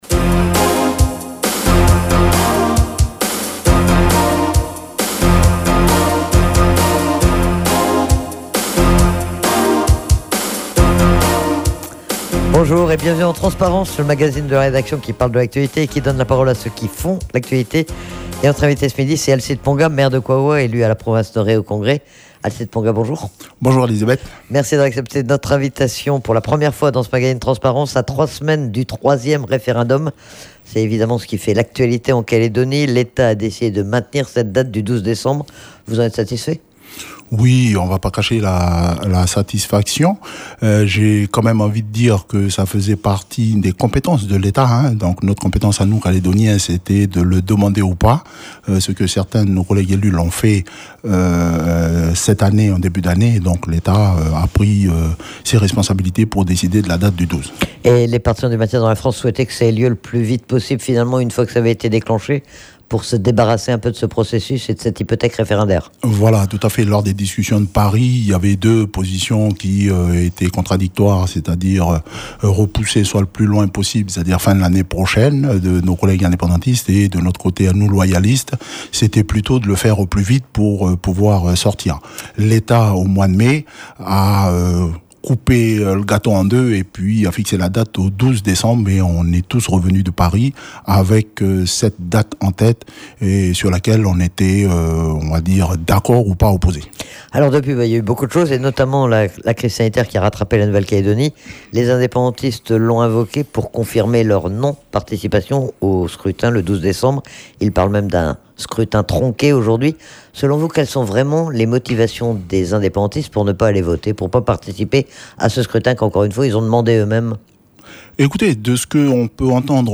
Le maire de Kouaoua et élu de la Province Nord, Alcide Ponga est interrogé sur l'actualité référendaire.